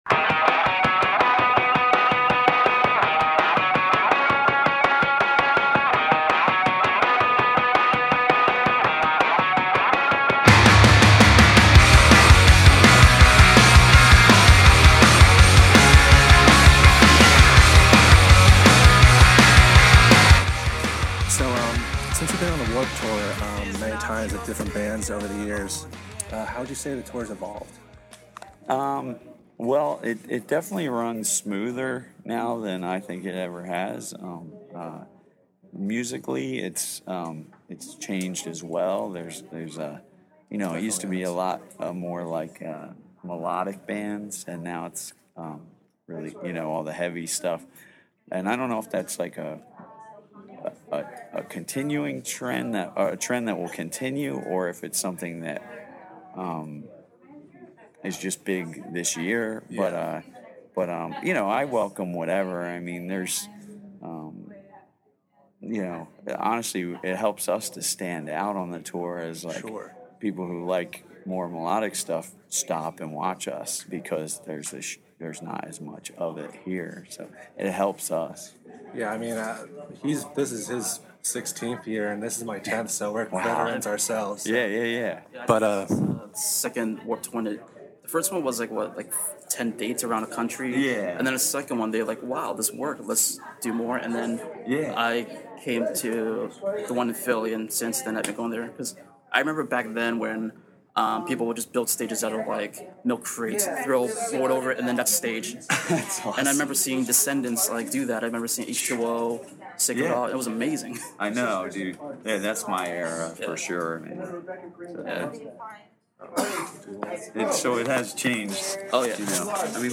Exclusive: TerribleThings Interview